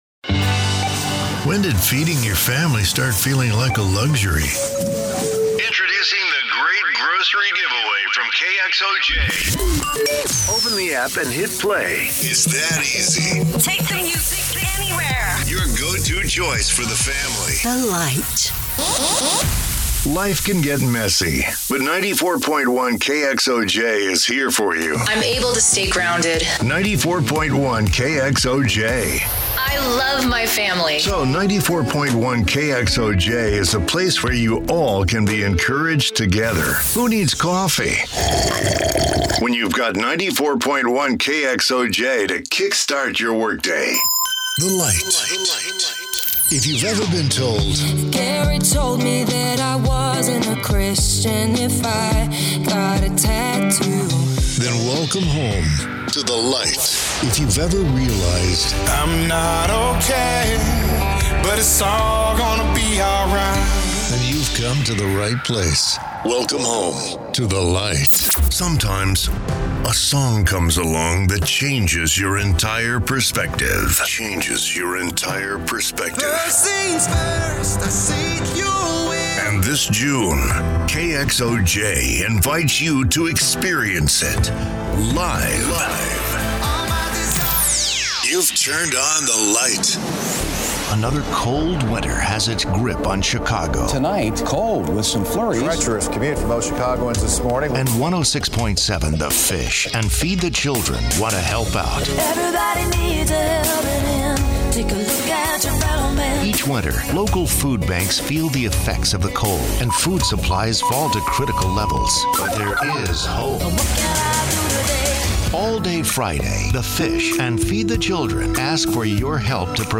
Smooth, warm, conversational, authentic and inviting, yet capably diverse
Radio Promos
Authentic Texan
Middle Aged
I have a great home studio with Neumann mic, Pro Tools, isolated vocal booth and Source Connect!!